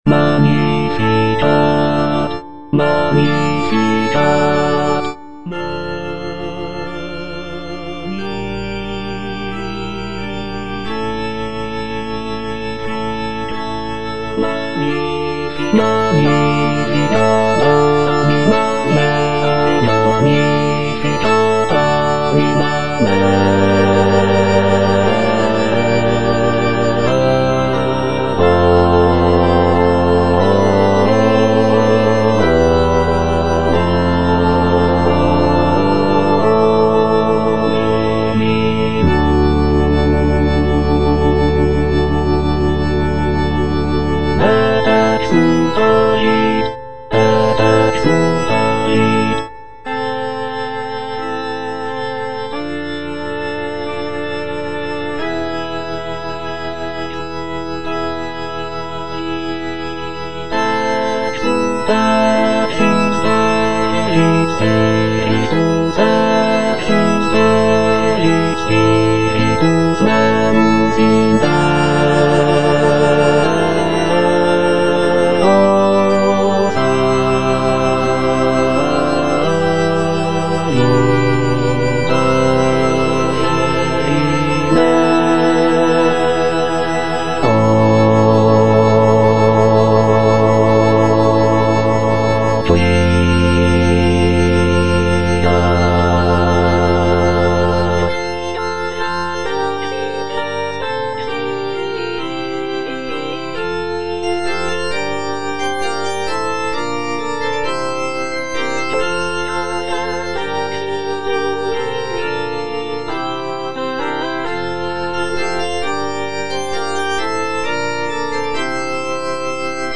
C. MONTEVERDI - MAGNIFICAT PRIMO (EDITION 2) Bass II (Emphasised voice and other voices) Ads stop: auto-stop